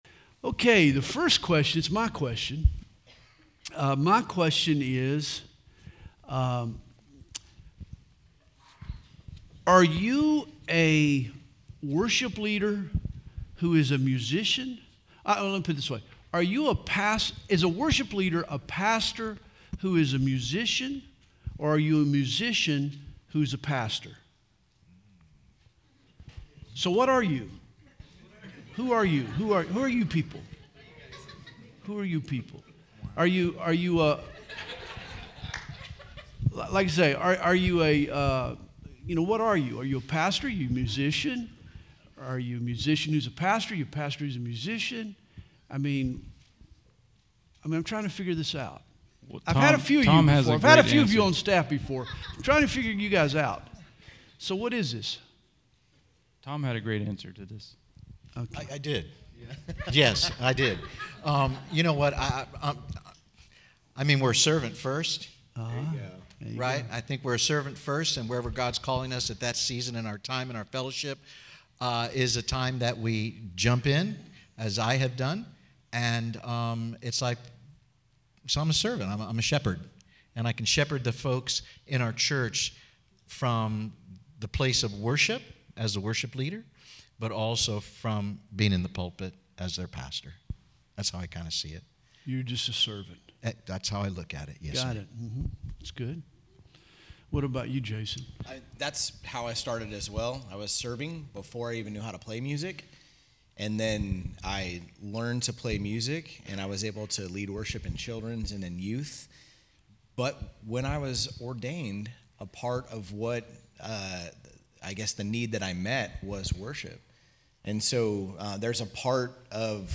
Q&A • The Heart Behind the Art
Conference: Worship Conference